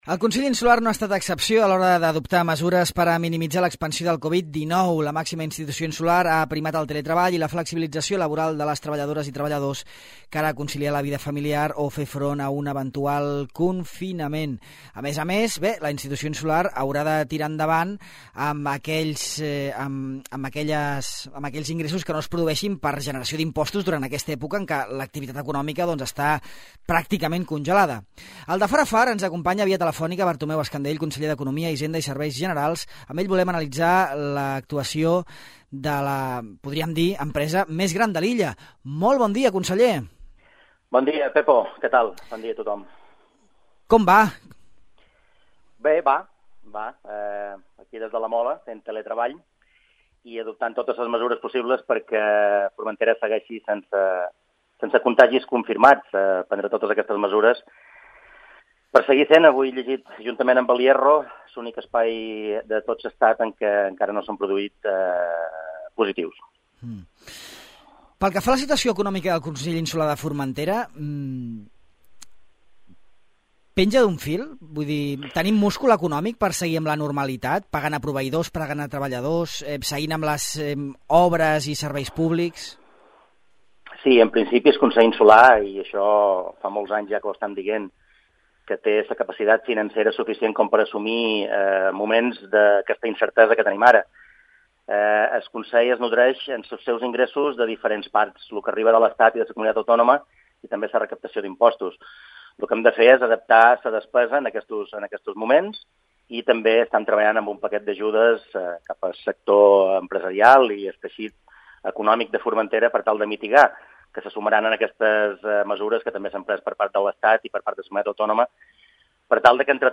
Al De Far a Far parlam amb en Bartomeu Escandell, conseller d’Economia i Hisenda. Amb ell volem analitzar l’actual situació del Consell i la seva plantilla, així com les repercussions que tindrà la pandèmia en l’economia i les economies de Formentera.